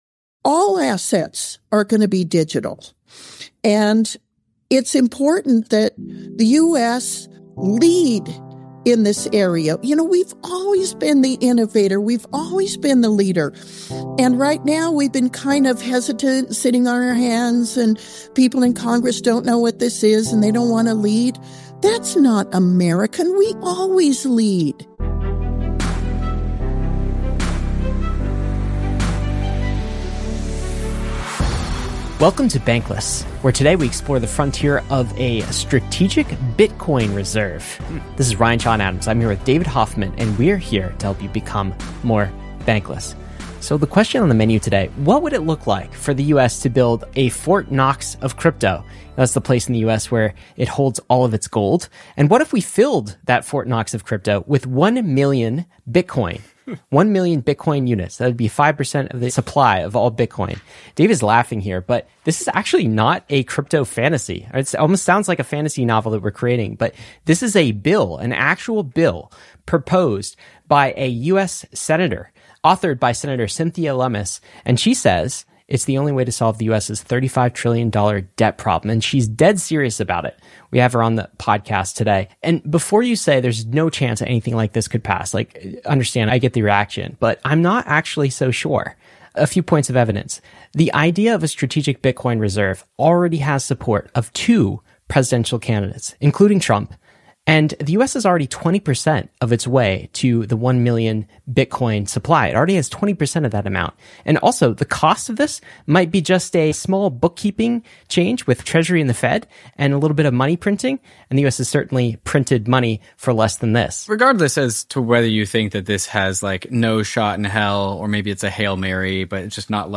Bankless chevron_right U.S. Strategic BTC Reserve - The BITCOIN Act | Senator Cynthia Lummis Sep 2, 2024 auto_awesome Senator Cynthia Lummis, a prominent advocate for Bitcoin in Congress, discusses her Bitcoin Act, aimed at establishing a strategic Bitcoin reserve for the U.S. to help tackle the national debt. She explores converting gold certificates into Bitcoin and the potential economic benefits of this shift. The conversation also highlights the importance of Bitcoin as 'digital gold,' examines bipartisan support, and critiques current government policies related to cryptocurrency.